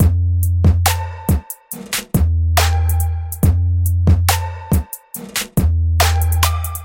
贵族 陷阱节拍
标签： 140 bpm Trap Loops Drum Loops 1.15 MB wav Key : Unknown
声道立体声